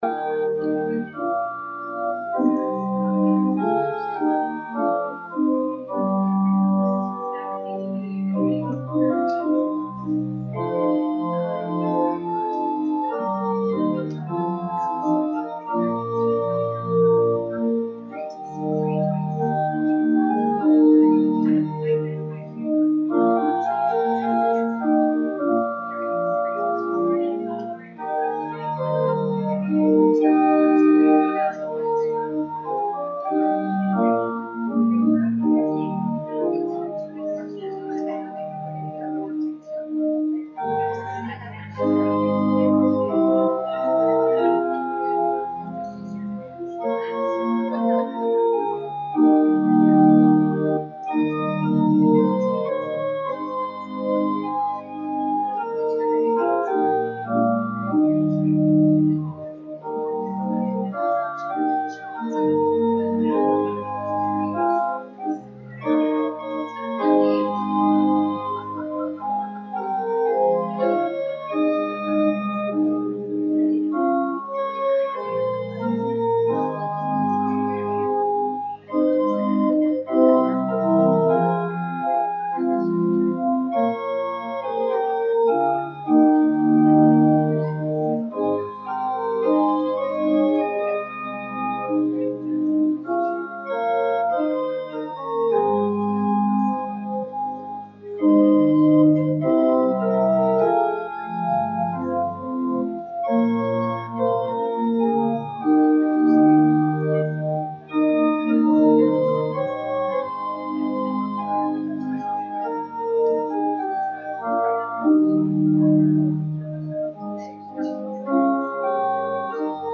RUMC-service-Apr-9-EASTER-CD.mp3